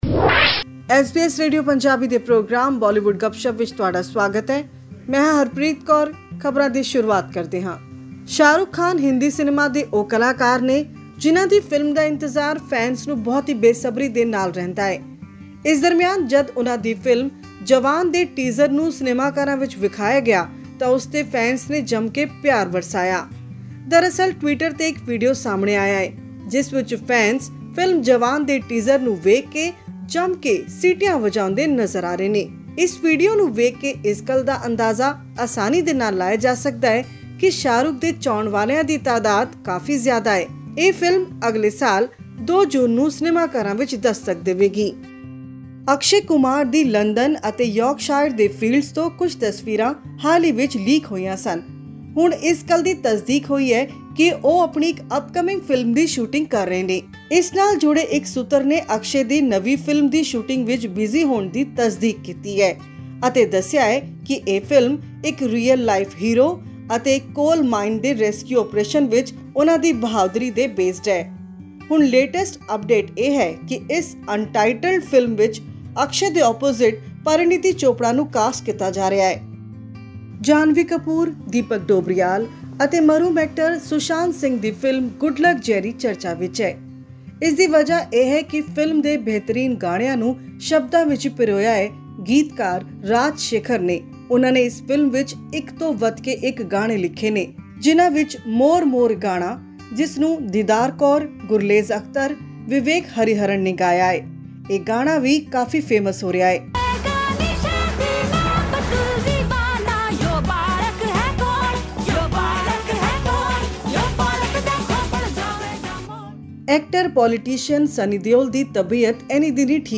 He received his initial treatment in Mumbai for a couple of weeks and then flew to the US for further treatment. This and much more are in our weekly news bulletin from Bollywood.